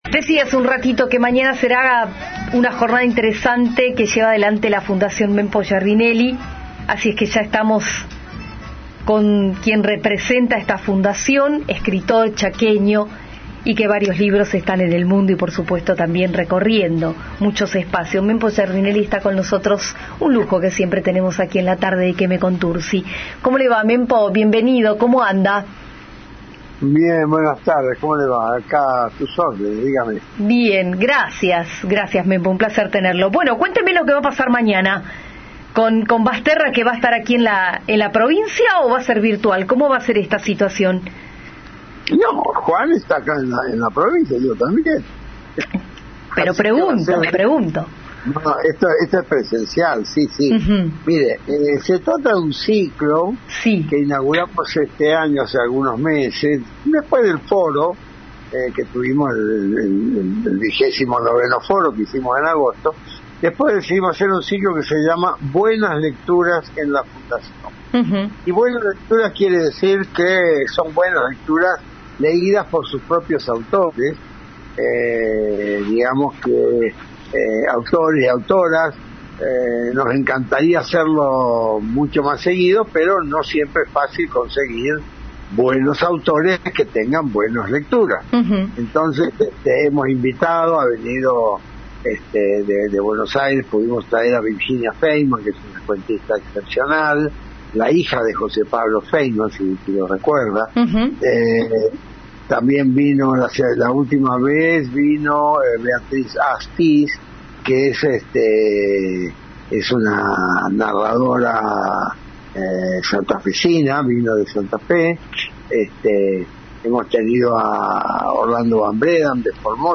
¡Escuchá la entrevista completa con Mempo Giardinelli!